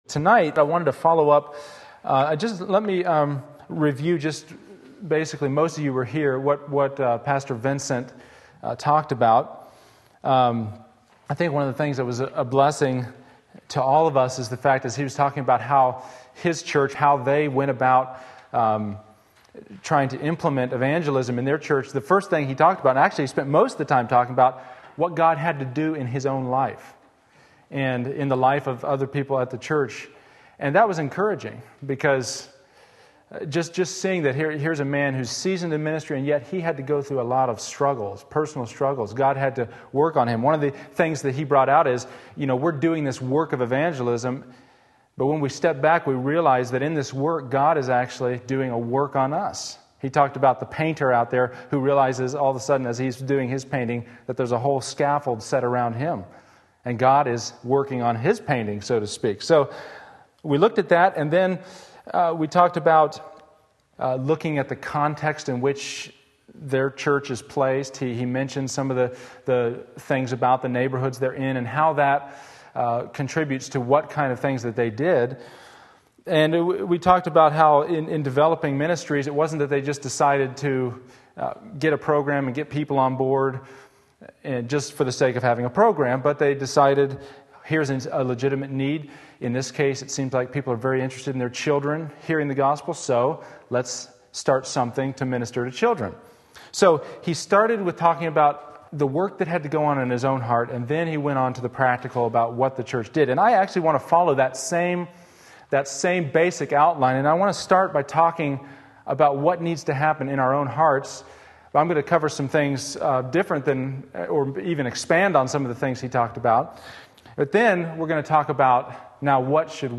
Sermon Link
Implementing Evangelism various texts Wednesday Evening Service